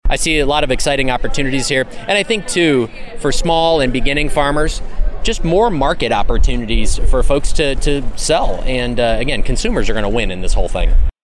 Naig was at the Iowa State Fair for its opening ceremonies Thursday. He touted the new “Choose Iowa” program.
Naig says consumers have been asking for and want to consider local when they are buying food.